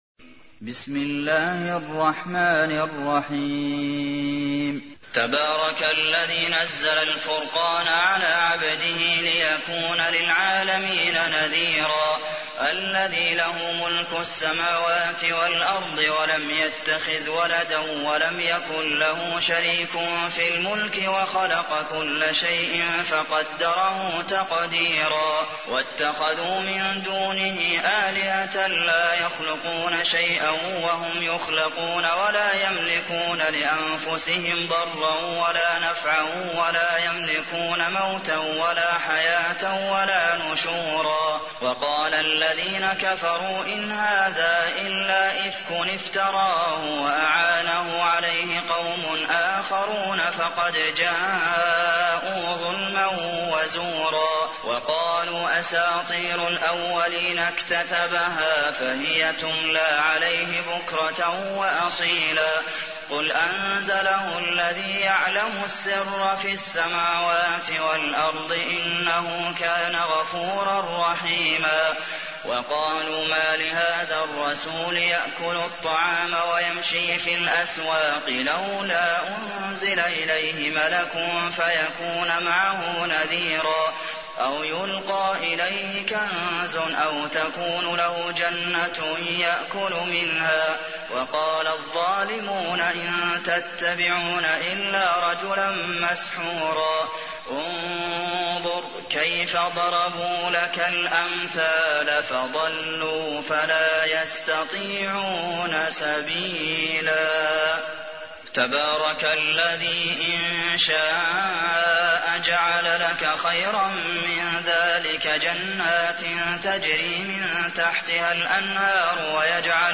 تحميل : 25. سورة الفرقان / القارئ عبد المحسن قاسم / القرآن الكريم / موقع يا حسين